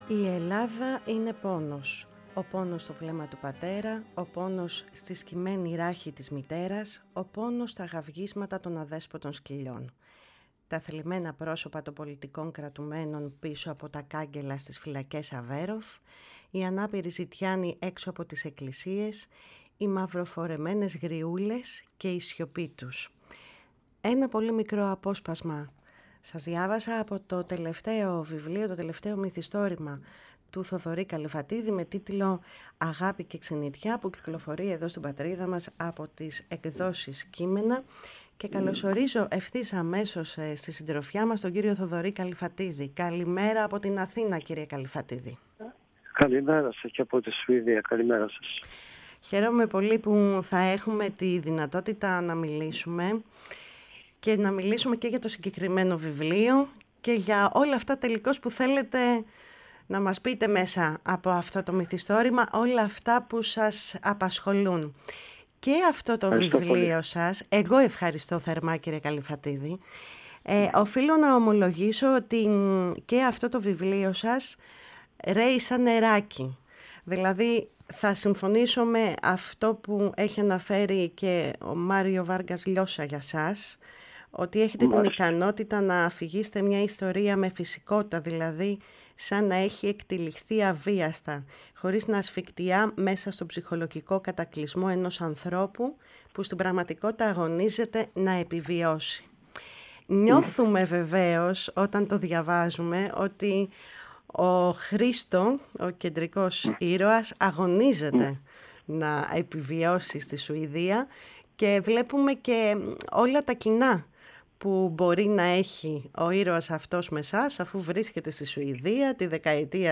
Ο ομογενής συγγραφέας Θοδωρής Καλλιφατίδης, φιλοξενήθηκε στη «Φωνή της Ελλάδας» και συγκεκριμένα στην εκπομπή «Κουβέντες μακρινές», με αφορμή το τελευταίο του μυθιστόρημα «Αγάπη και Ξενιτιά» που κυκλοφόρησε πρόσφατα από τις εκδόσεις Κείμενα.